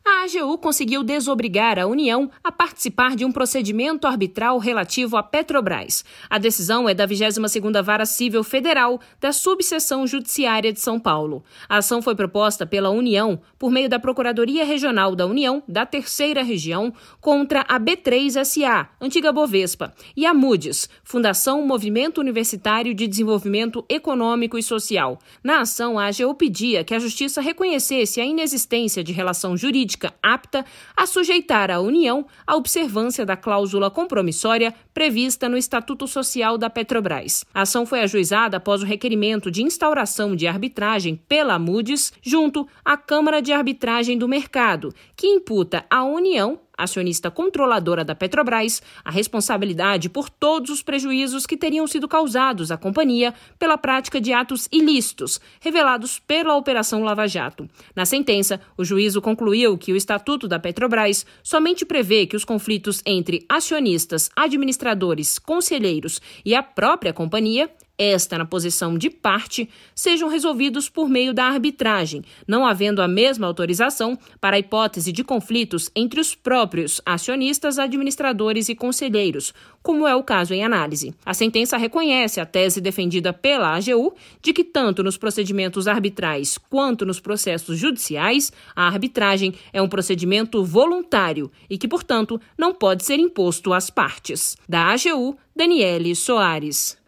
Acompanhe na reportagem